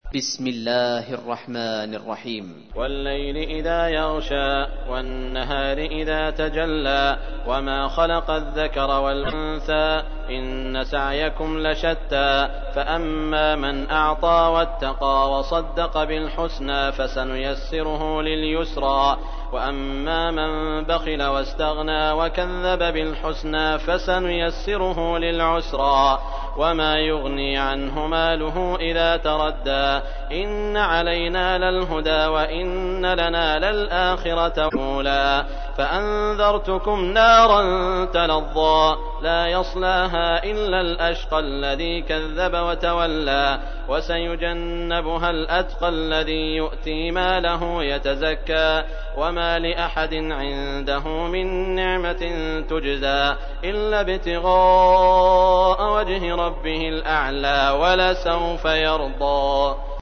تحميل : 92. سورة الليل / القارئ سعود الشريم / القرآن الكريم / موقع يا حسين